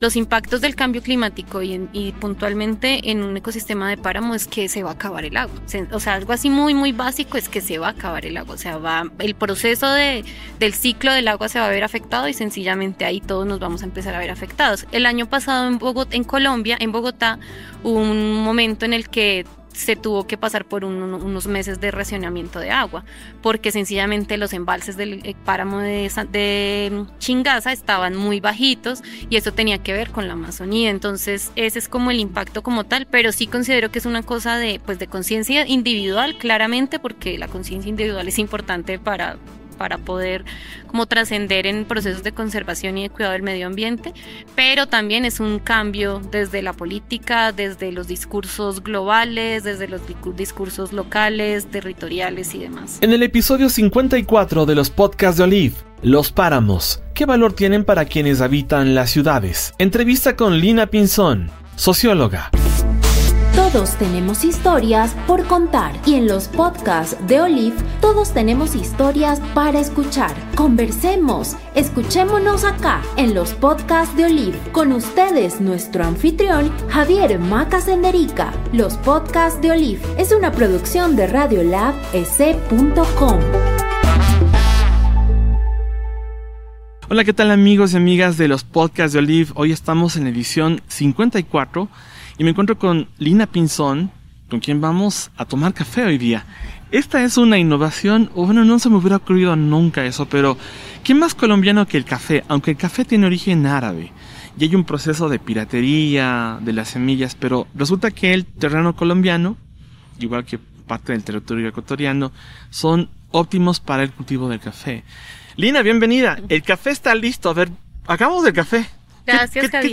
La naturaleza tiene derechos, y en Ecuador, esta figura está presente en la constitución. Más allá de Ecuador, ¿cómo se vive esta realidad en otros países, como por ejemplo en Colombia? Para conversar de ello, de los Páramos y los ecosistemas